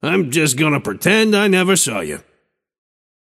Shopkeeper voice line - I’m… just gonna pretend I never saw you.
Shopkeeper_hotdog_t4_seven_03.mp3